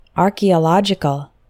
Fast: